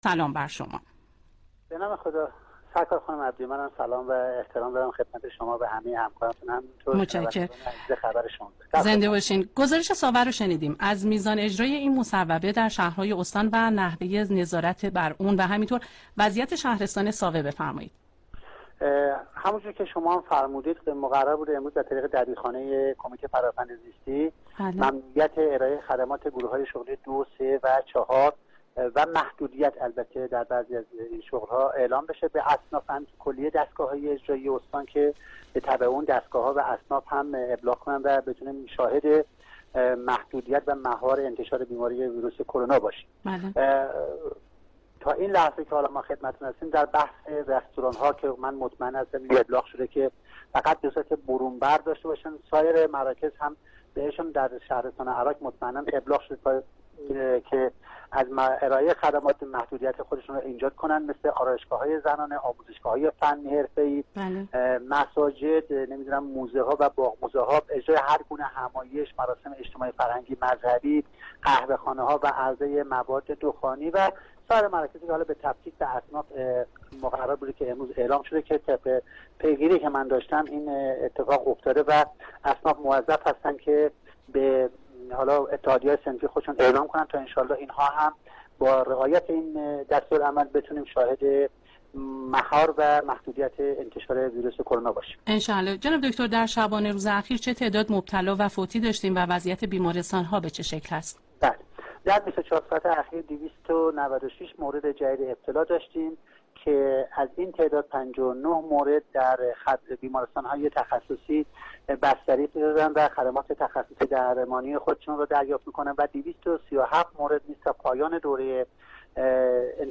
در گفتگوی تلفنی با خبر ۱۶ صدای استان مرکزی